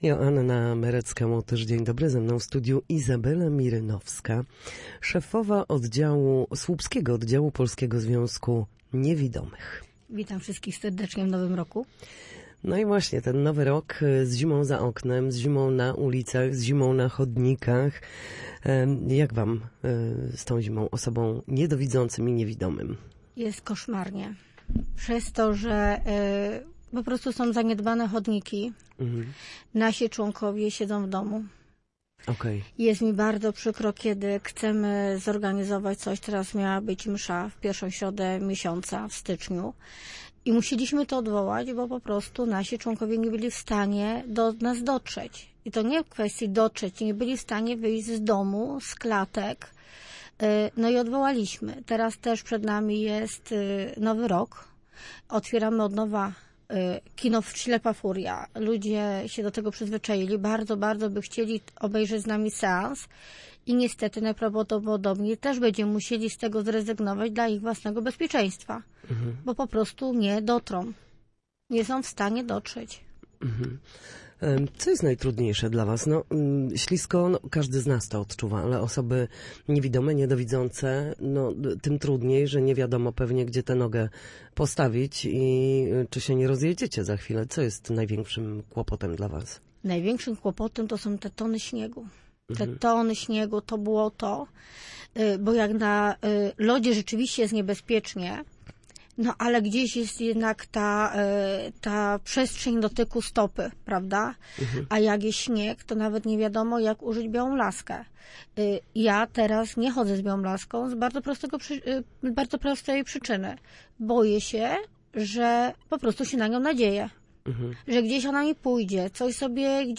Zima zamyka osoby niewidome w domach, bariery na chodnikach i ulicach odbierają im samodzielność. O tym w Studiu Słupsk mówiła